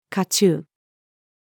華冑-female.mp3